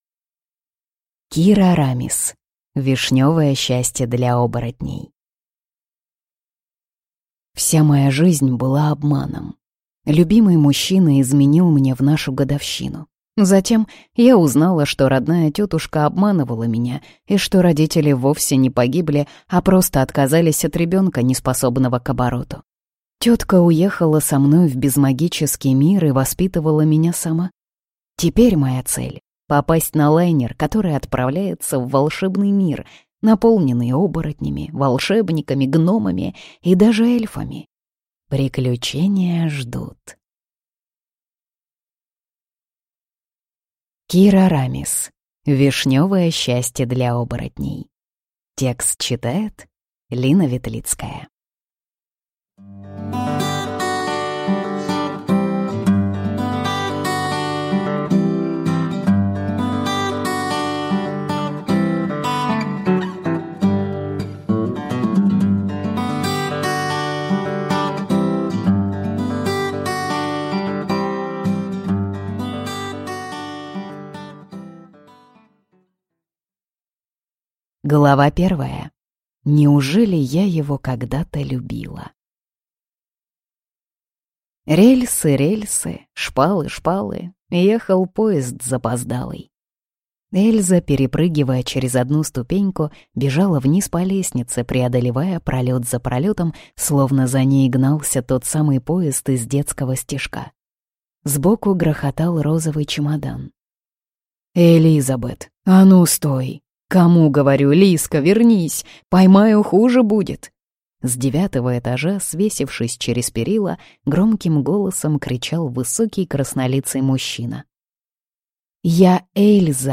Аудиокнига Вишнёвое счастье для оборотней | Библиотека аудиокниг